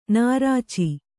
♪ nārāci